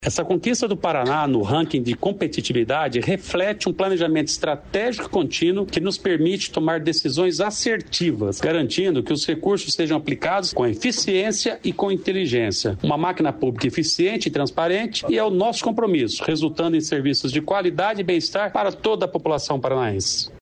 Sonora do secretário do Planejamento, Ulisses Maia, sobre o Ranking de Competitividade dos Estados